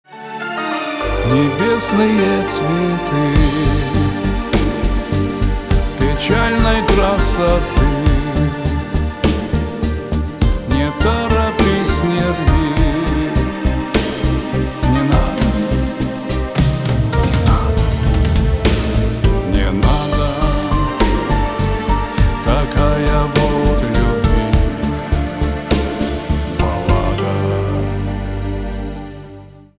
шансон
гитара , барабаны , грустные
печальные